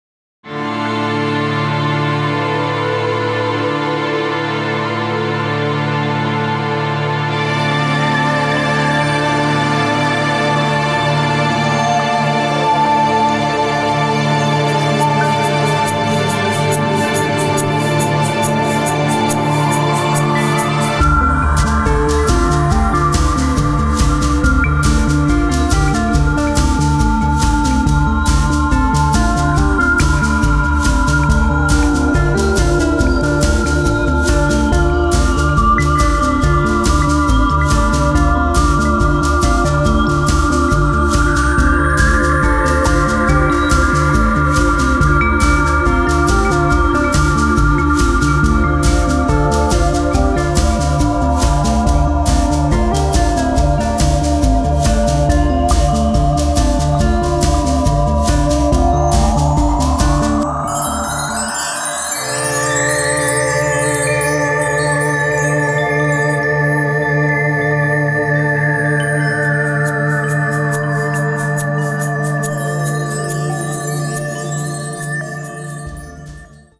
Keyboards
Guitar, Bass